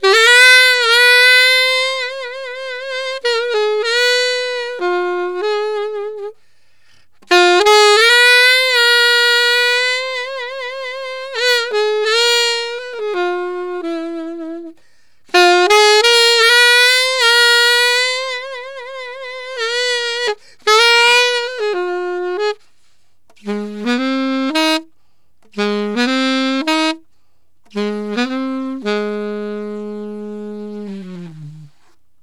Alto One Shot in Ab 03.wav